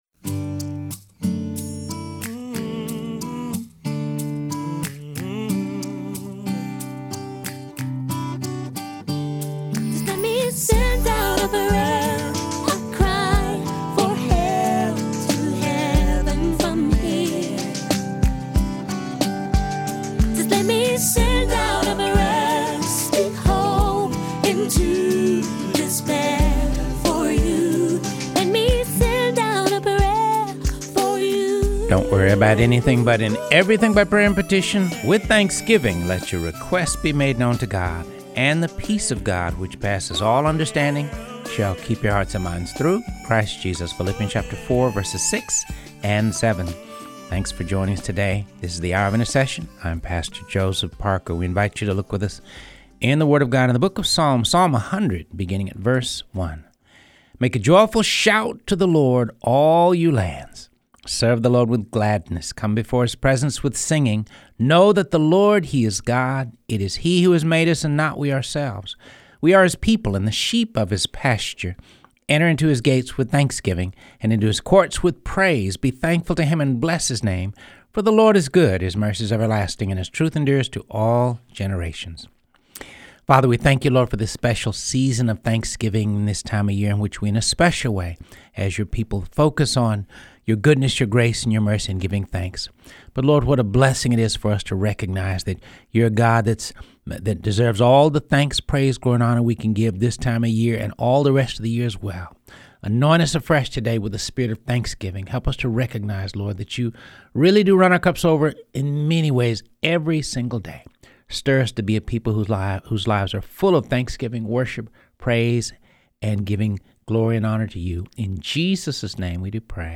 Reading through the Word of God, ep. 152